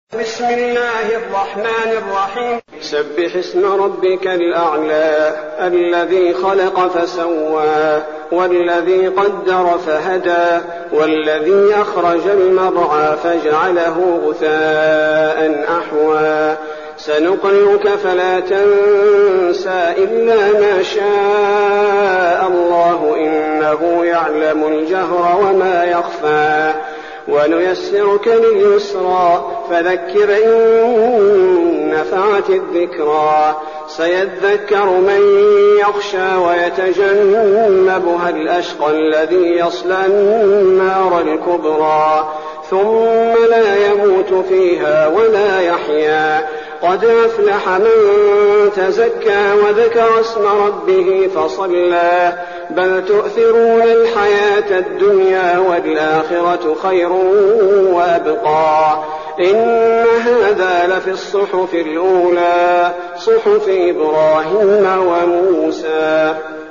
المكان: المسجد النبوي الشيخ: فضيلة الشيخ عبدالباري الثبيتي فضيلة الشيخ عبدالباري الثبيتي الأعلى